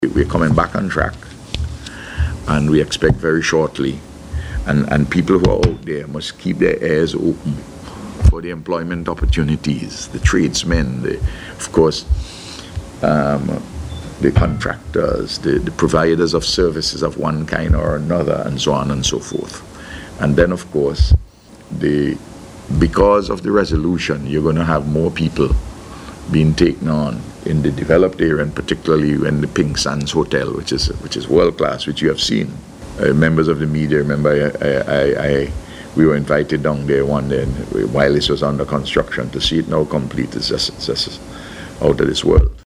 In making the announcement at this morning’s News Conference, the Prime Minister read a letter from one of the Investors, Dermott Desmond, thanking him for his efforts to settle the conflict.